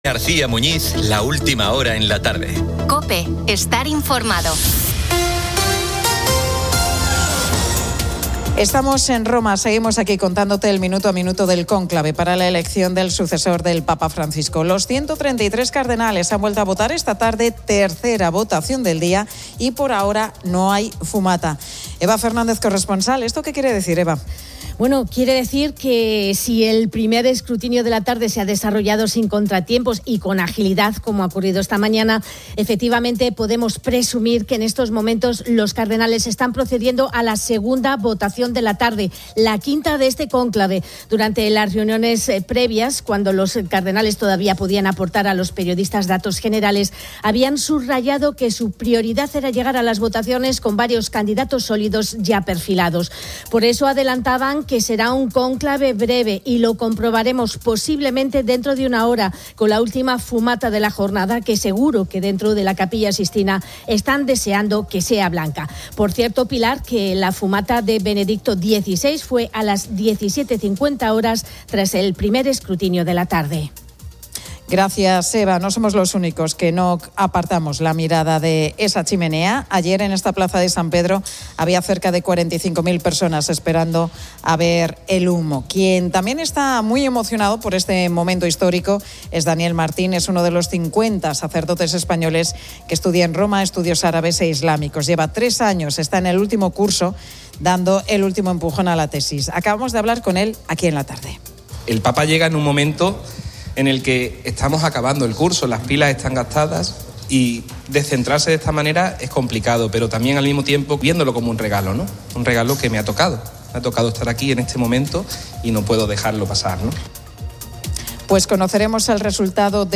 La Tarde 18:00H | 08 MAY 2025 | La Tarde Pilar García Muñiz y el equipo de La Tarde viven en directo desde Roma la fumata blanca y la elección del cardenal Robert Prevost como nuevo Papa.